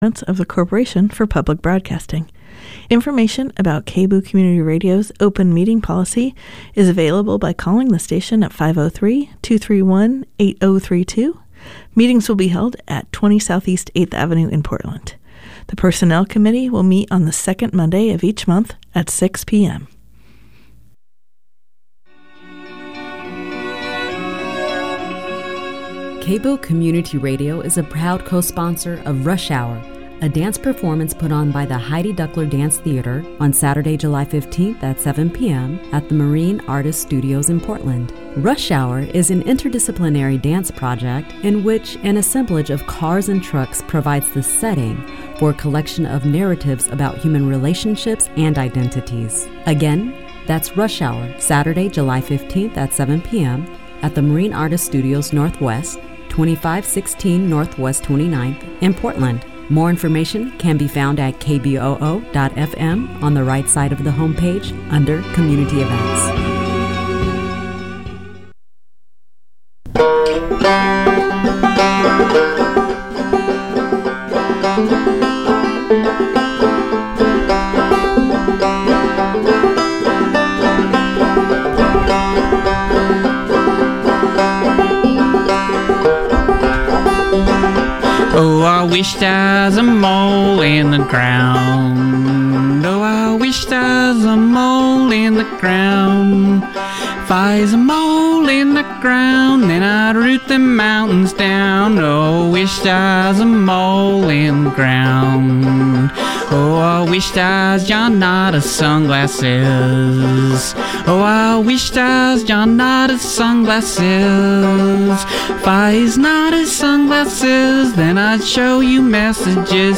news, interviews, commentary and reviews from a feminist/socialist perspective